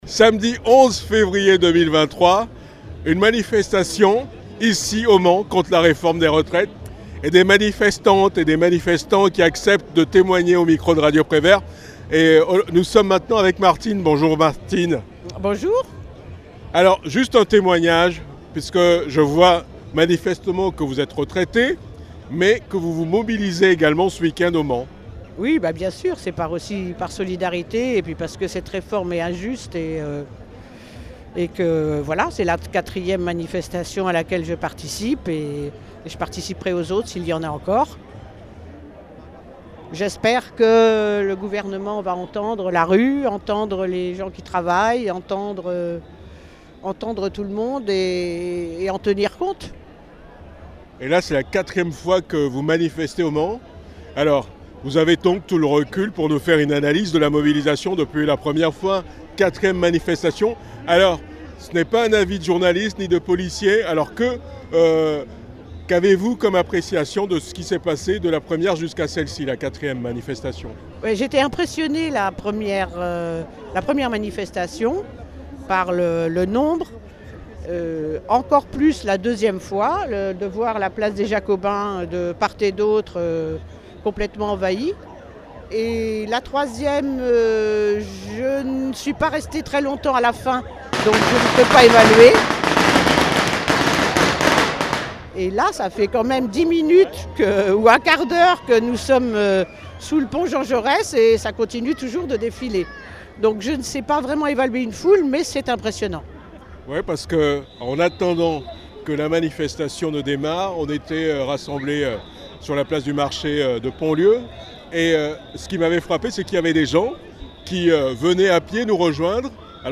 11 février 2023 : manifestation contre la réforme des retraites au Mans
Manifestation réforme des retraites - Intermittente et Service civique